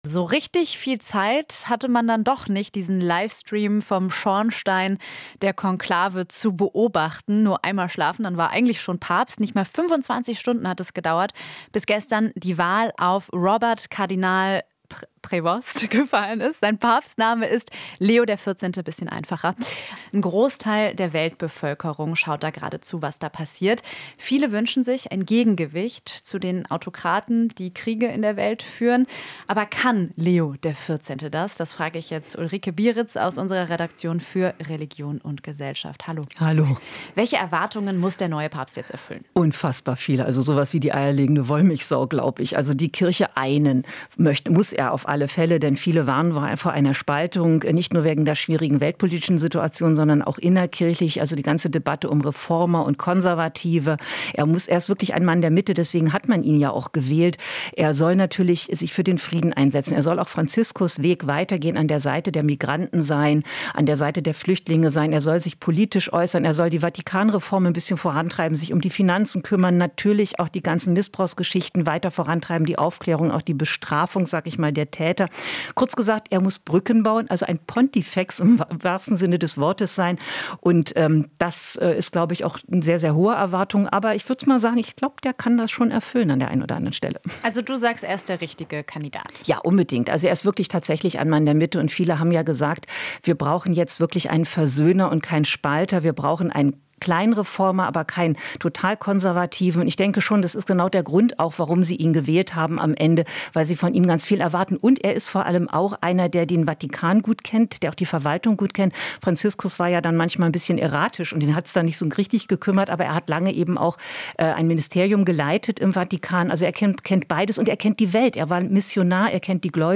Interview - Was erwartet die Welt vom neuen Papst? | rbb24 Inforadio